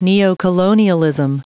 Prononciation du mot neocolonialism en anglais (fichier audio)